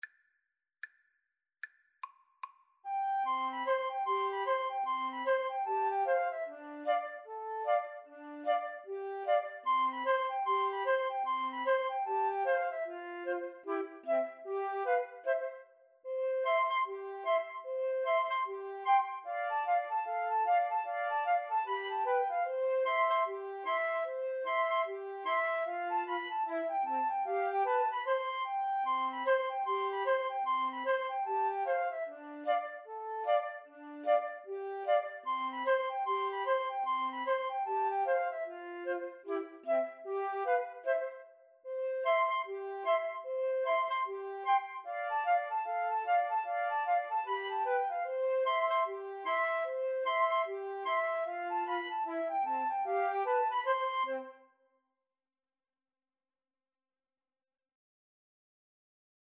C major (Sounding Pitch) (View more C major Music for Flute Trio )
Flute Trio  (View more Intermediate Flute Trio Music)